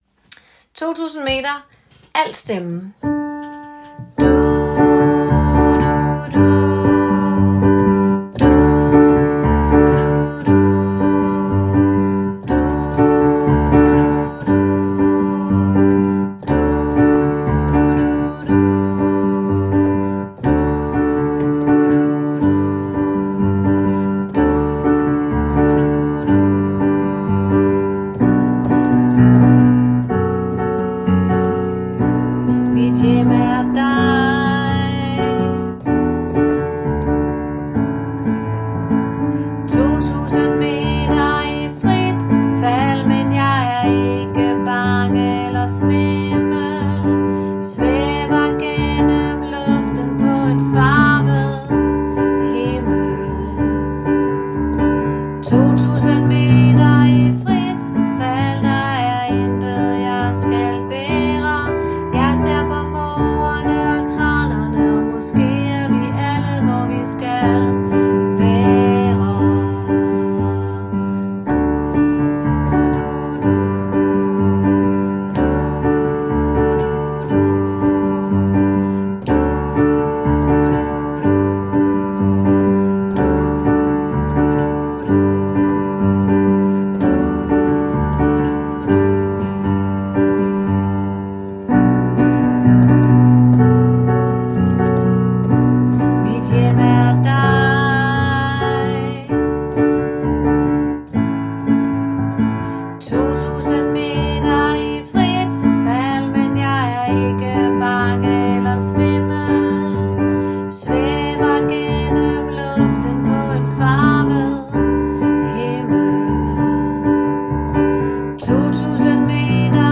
2000 meter – alt